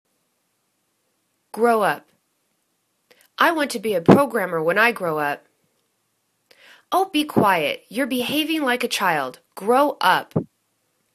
grow up     /gro: up/    phrasal verb